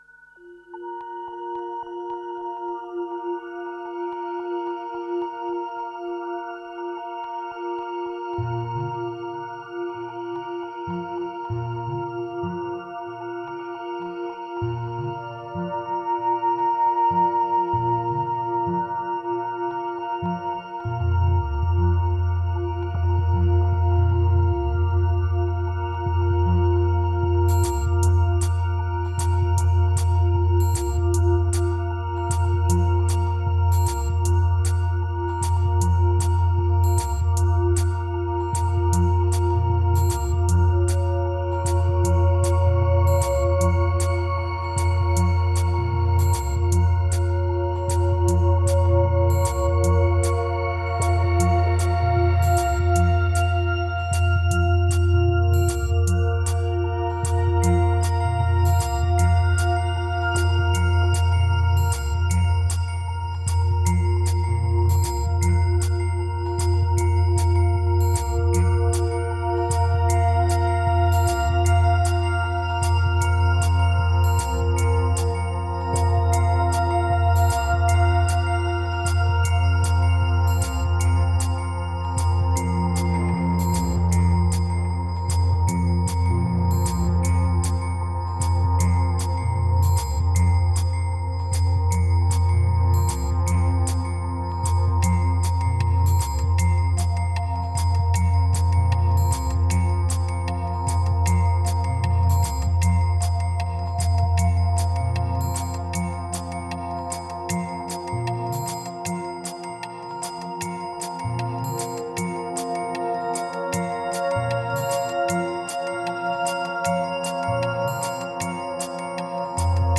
2253📈 - -10%🤔 - 77BPM🔊 - 2014-03-10📅 - -352🌟